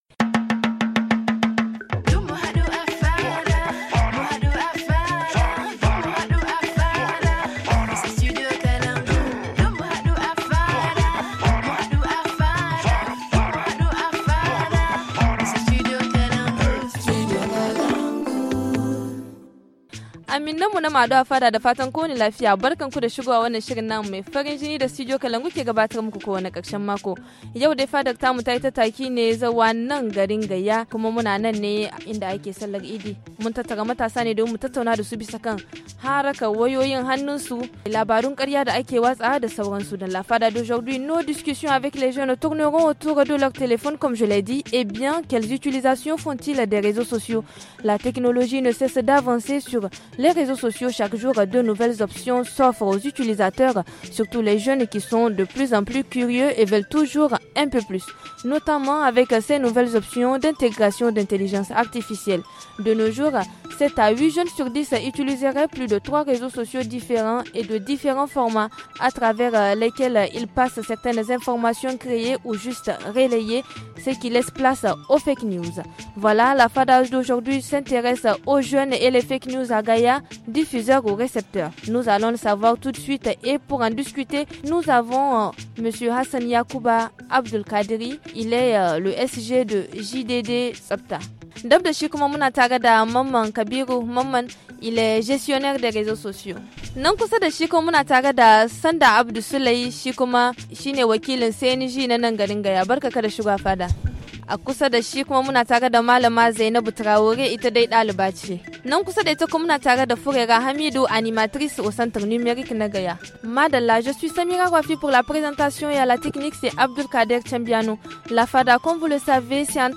Dans la fada d’aujourd’hui, qui nous amène dans le département de Gaya région de Dosso, nos discussions avec les jeunes tourneront autour de leur téléphone et bien quelle utilisation font-t-ils des réseaux ?